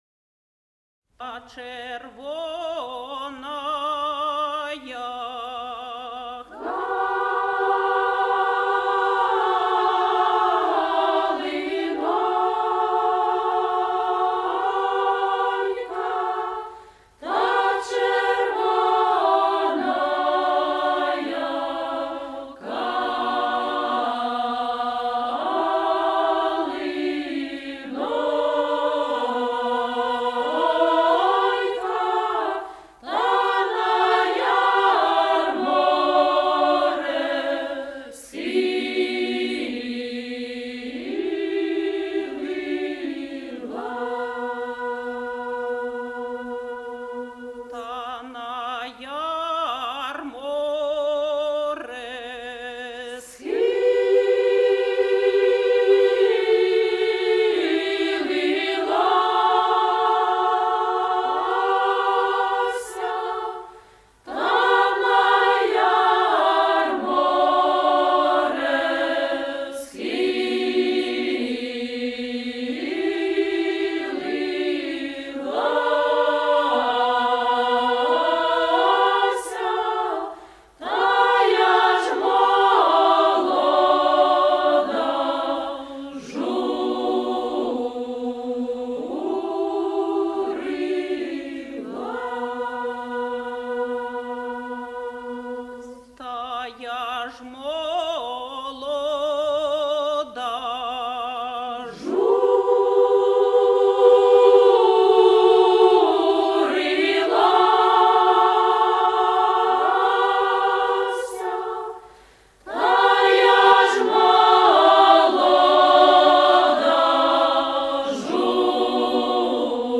Украинская народная песня